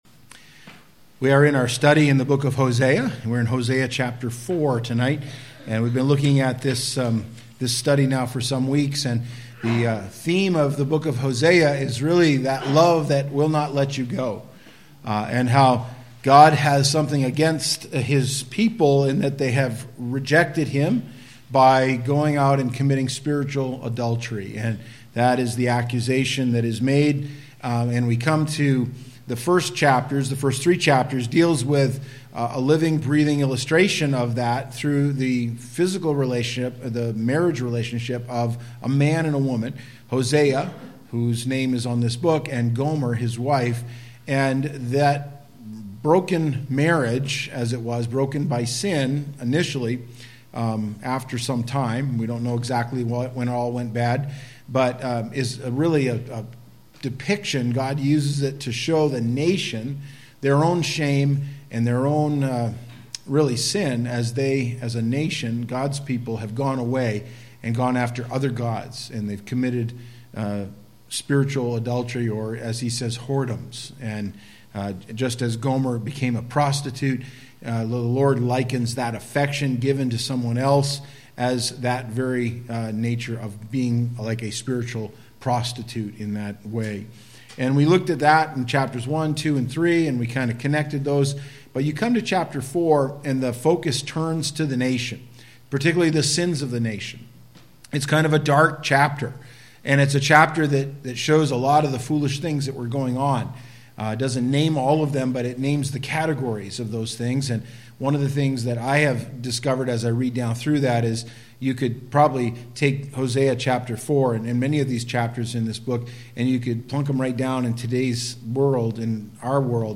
Sermons by Madawaska Gospel Church